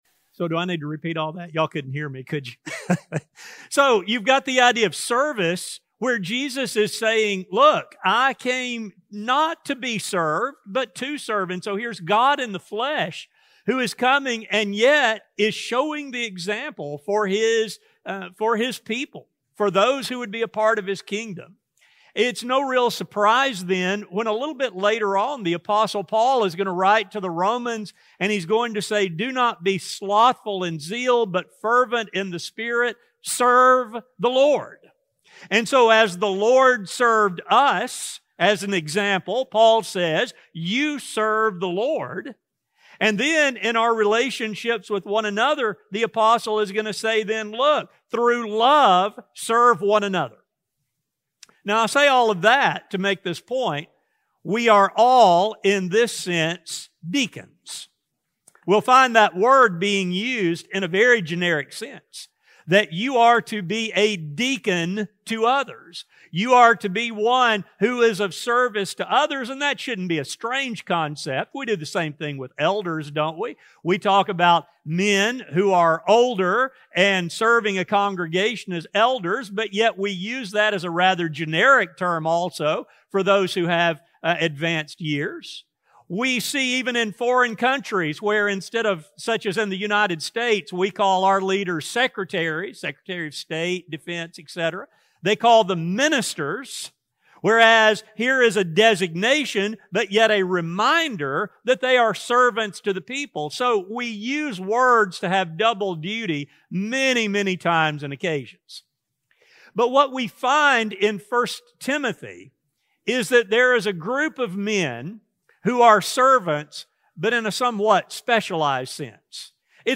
While this is expected of all Christians, deacons are specifically called on to serve the needs of a congregation. In this sermon we discuss what it means to serve as a deacon and what type of men God calls on to serve this important role.